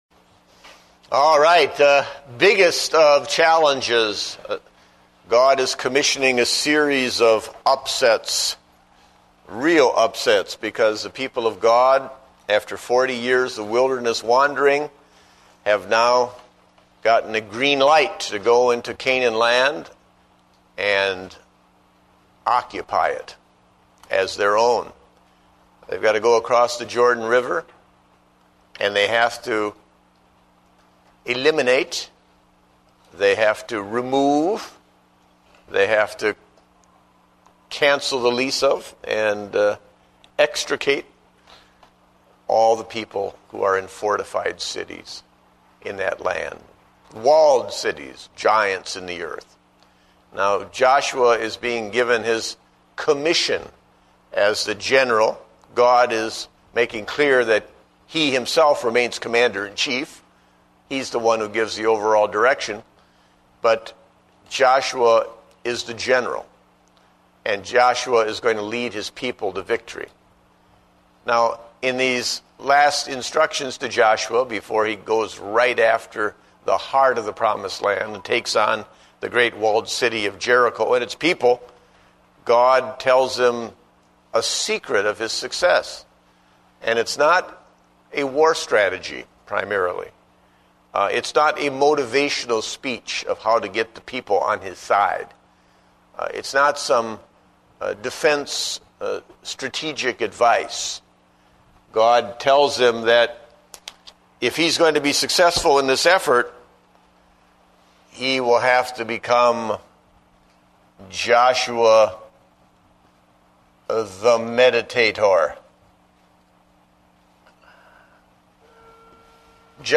Date: July 19, 2009 (Adult Sunday School)